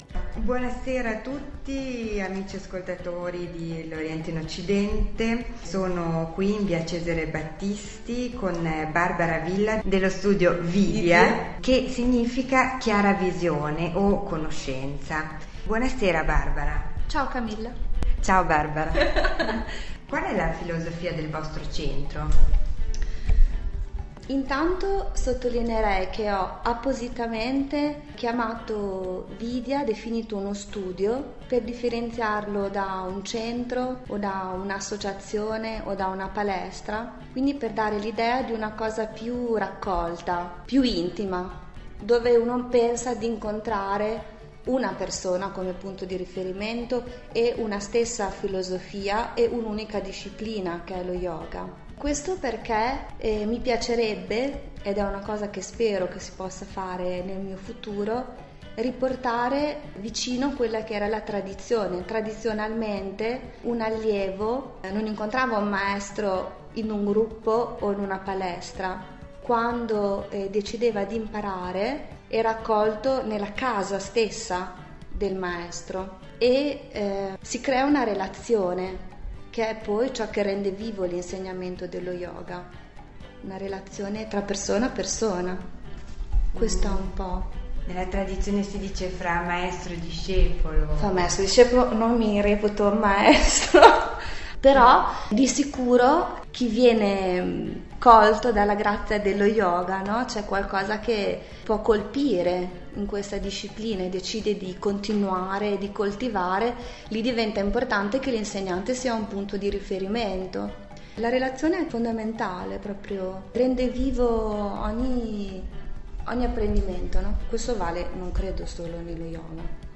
Sullo yoga, intervista